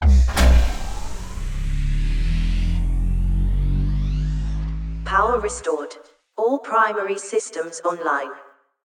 SeabasePowerUp.ogg